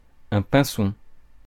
Fr-pinson.ogg.mp3